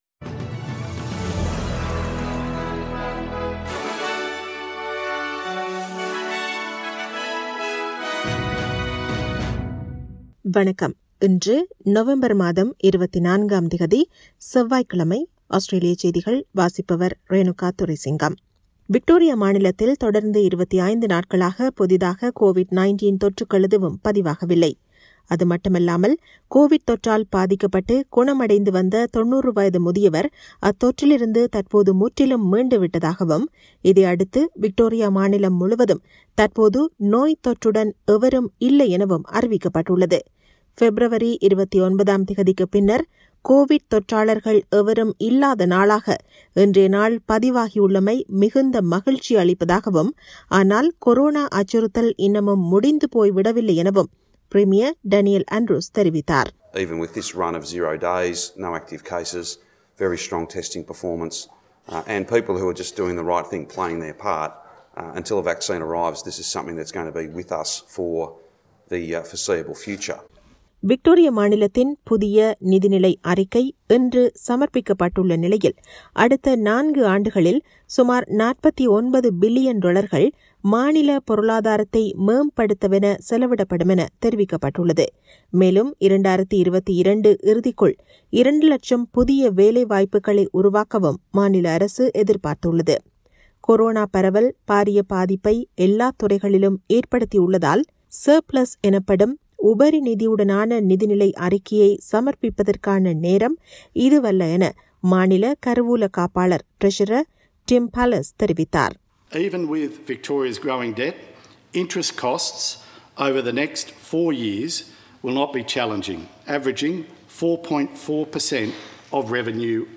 Australian news bulletin for Tuesday 24 November 2020.